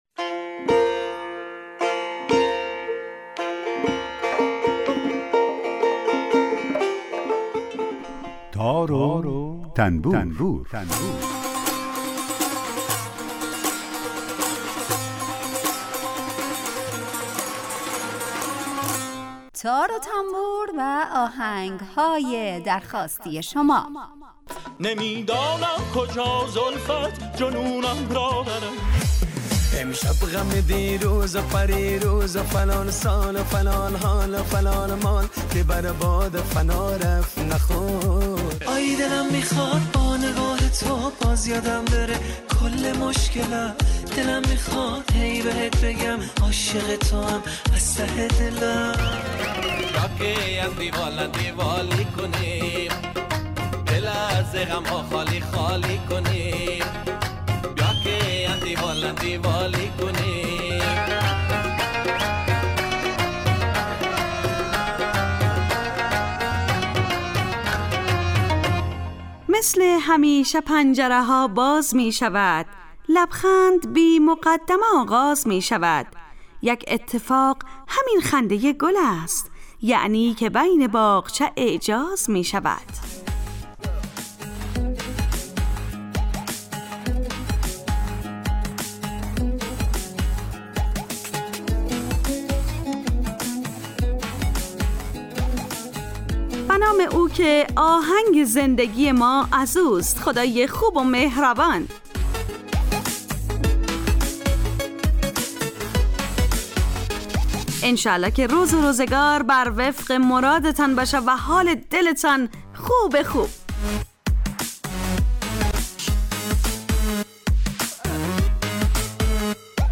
هر روز از رادیو دری به مدت 40 دقیقه برنامه ای با آهنگ های درخواستی شنونده ها
یک قطعه بی کلام درباره همون ساز هم نشر میکنیم